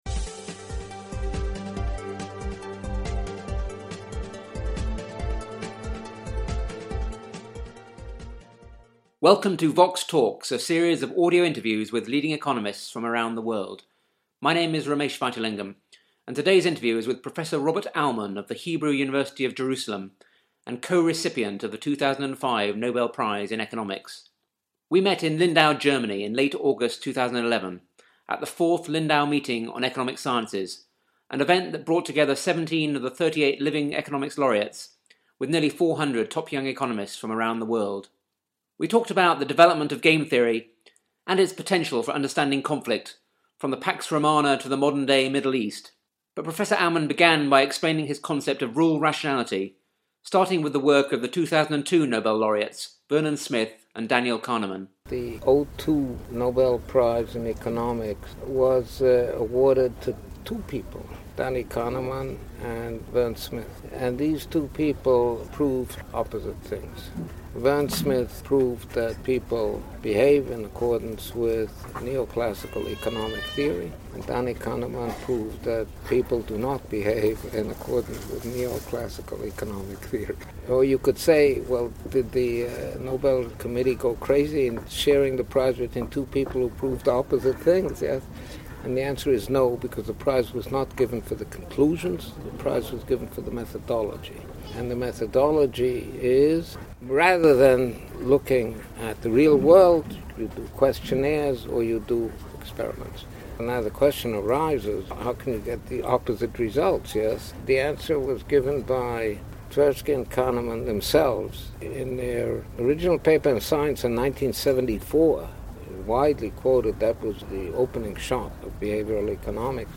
The interview was recorded in August 2011 at the Fourth Lindau Meeting on Economic Sciences, which brought together 17 of the 38 living economics laureates with nearly 400 top young economists from around the world.